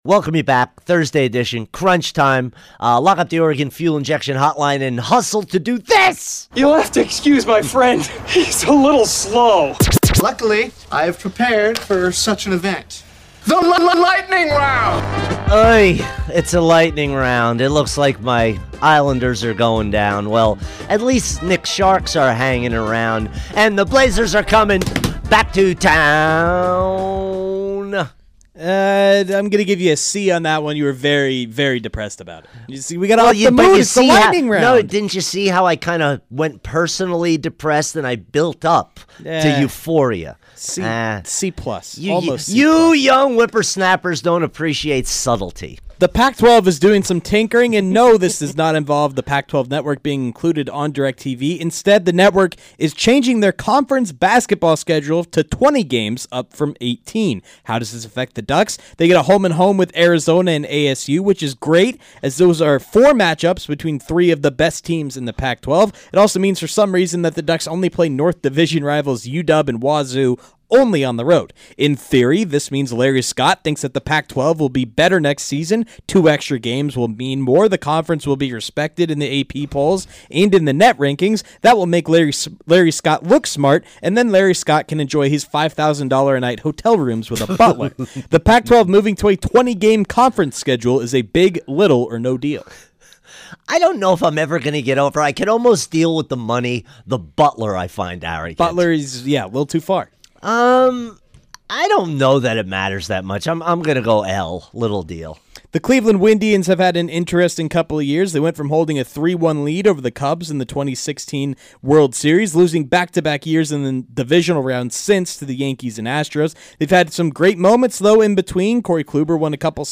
rapid-fire style